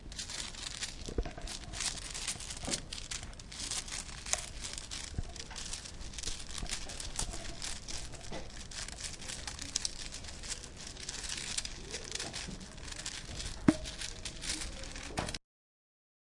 焚烧塑料袋Zilch的声音1
描述：几个塑料袋"zilches" 着火的声音。自然界的声音，背景是鸟类。 2月21日清晨，克拉克福克河附近。
Tag: 烧伤 消防 现场录音 噪音 Plasting